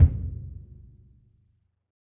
BDrumNewhit_v3_rr1_Sum.wav